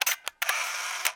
the-camera-shutter-sound